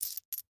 Coins Moving in Hand Sound
household
Coins Moving in Hand